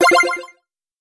Download Video Game Bit sound effect for free.
Video Game Bit